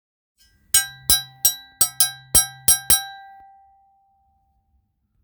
●ベル
ケスマ 鉄指ドラム
ケスマは本体を人差し指に、リングを親指に装着し、リズムを奏でる楽器です。
素材： 鉄